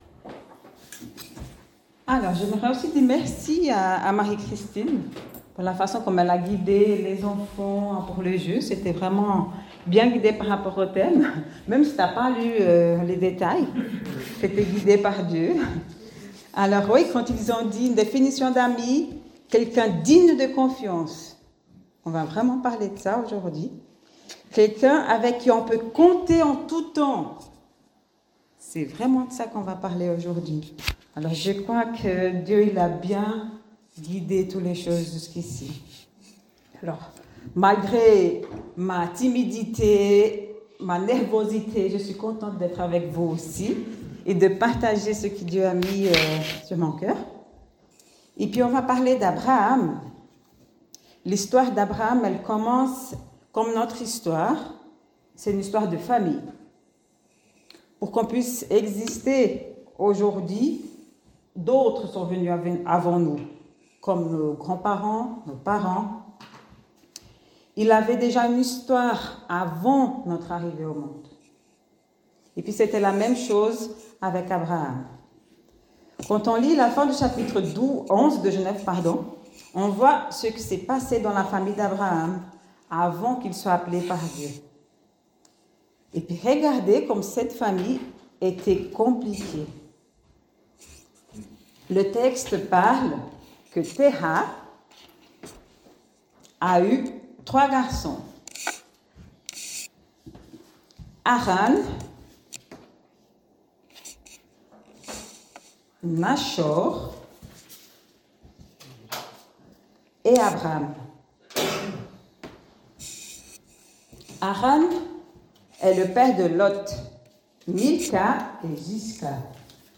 Dans cette nouvelle série, nous suivrons son chemin, depuis son appel jusqu’à la naissance d’Ismaël. Viens vivre un culte joyeux et accessible à tous, où les enfants sont plus que bienvenus !